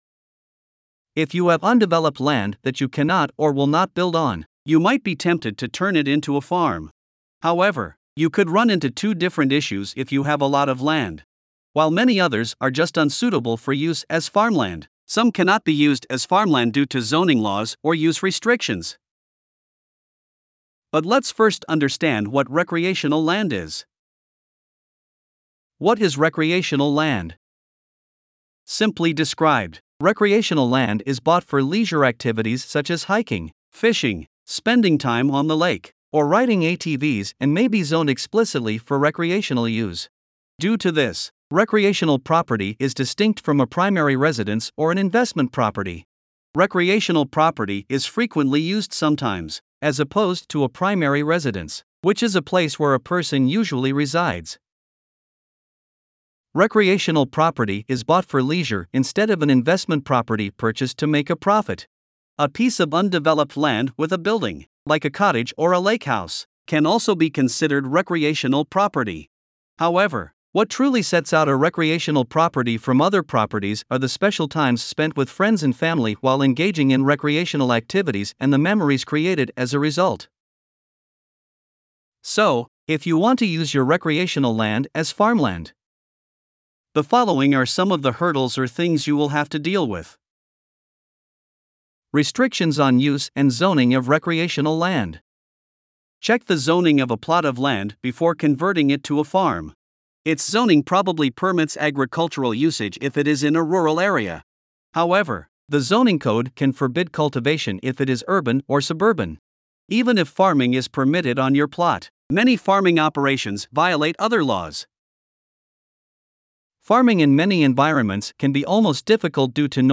Voiceovers-Voices-by-Listnr.mp3